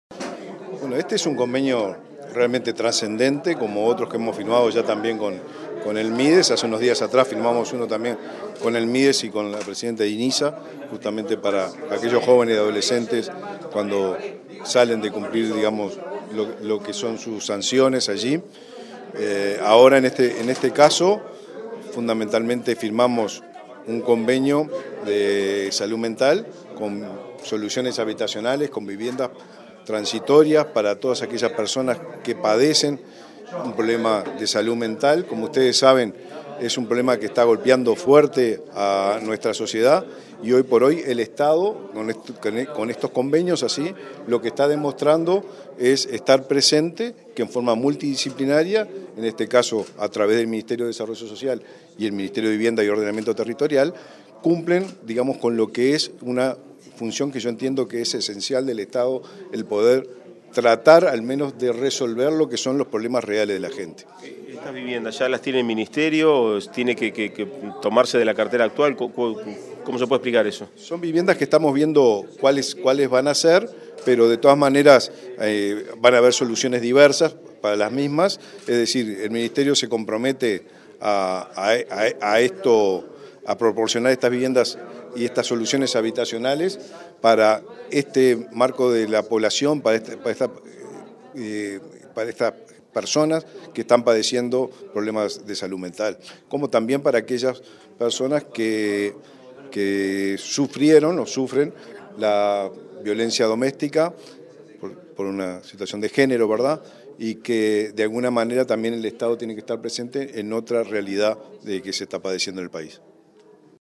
Declaraciones del ministro de Vivienda y Ordenamiento Territorial, Raúl Lozano